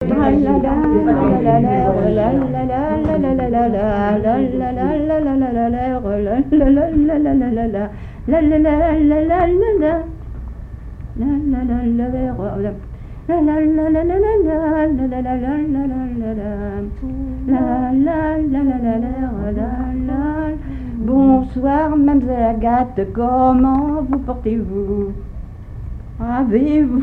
Divertissements d'adultes - Couplets à danser Résumé : Bonjour tante Perrine, comment vous portez-vous ? Vous me faites la mine, dites-moi qu'avez-vous ? C'est mon amant qu'est parti ce matin, voilà qui me fait de la peine.
danse : polka piquée
répertoire de chansons